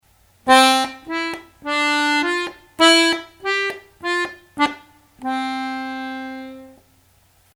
do acento-mi; re-fa con ligadura; mi con acento; fa con acento